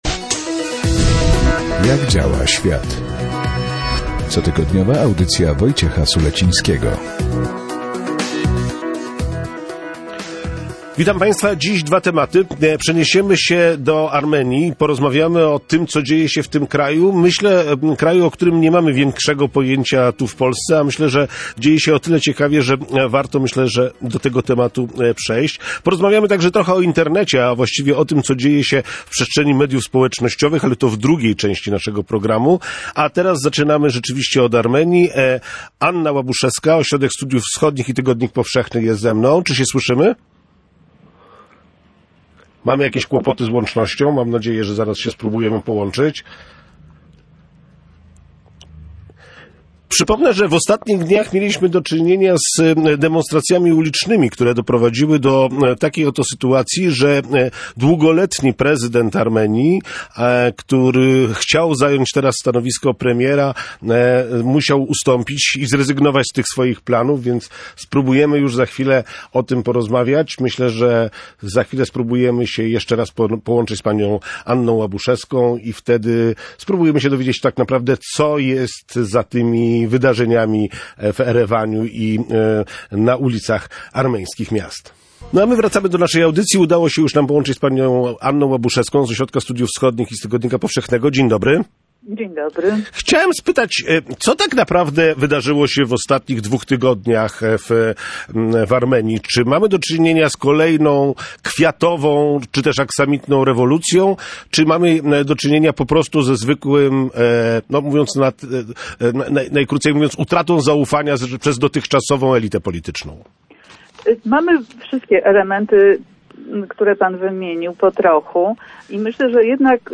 Był to jeden z tematów audycji Jak działa świat. Drugim poruszonym w rozmowie tematem były media społecznościowe – afera z Cambridge Analytica.